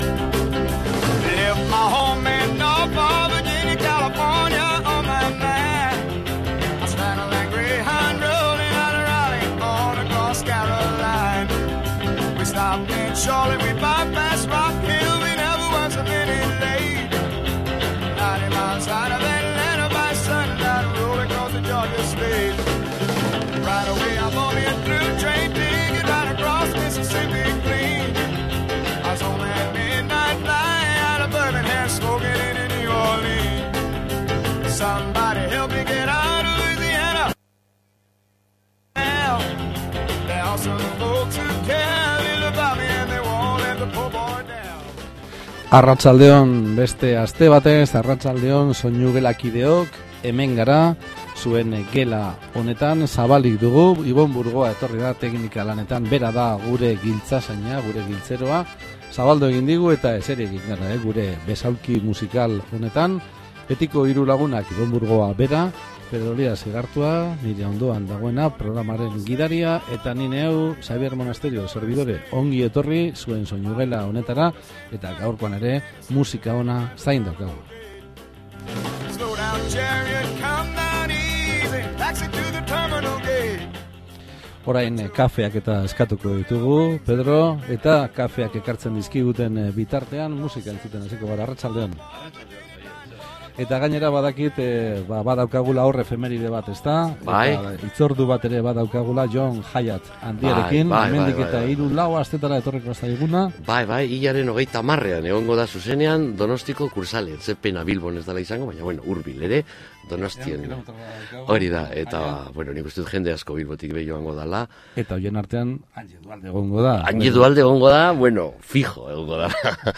Lehenbiziko orduan folk giroan murgilduta ibili gara